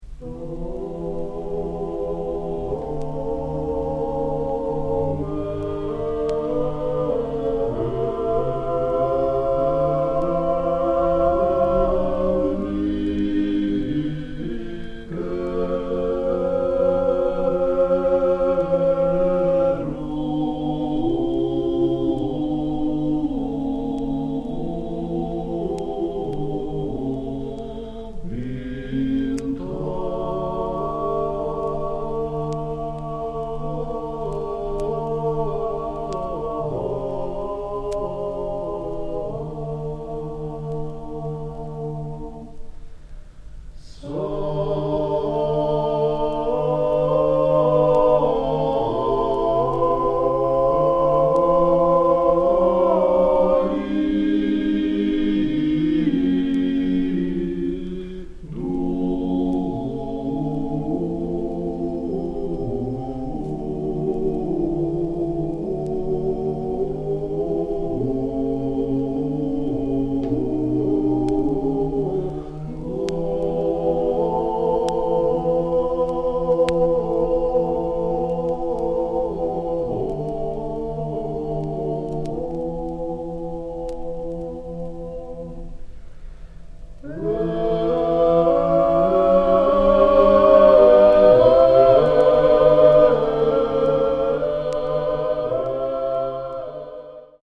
多声合唱の最も古い形のひとつと云われるジョージアの伝承歌。
異なる特徴を持つ各地域ごとの録音で構成。
現地録り